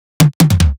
VR_drum_fill_dramaqueen_150.wav